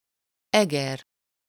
Eger (UK: /ˈɛɡər/ EG-ər,[2] US: /ˈɡər/ AY-gər;[3][4] Hungarian: [ˈɛɡɛr]